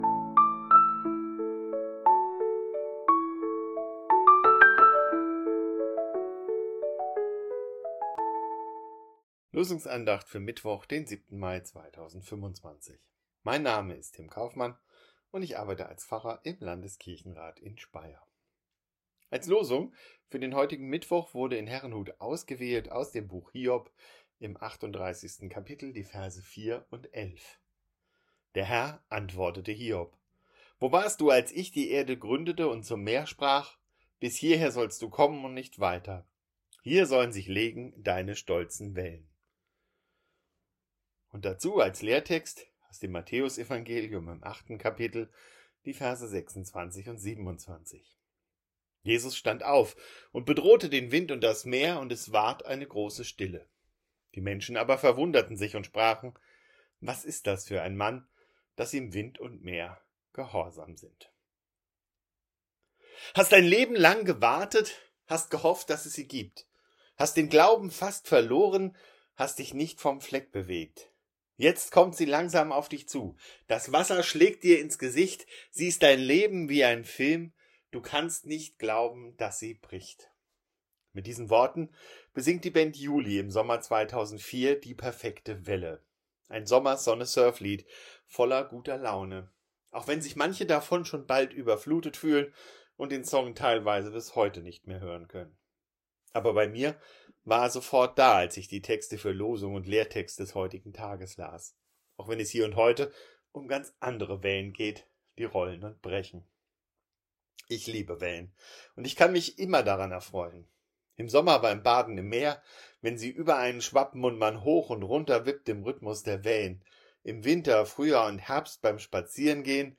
Losungsandacht für Mittwoch, 07.05.2025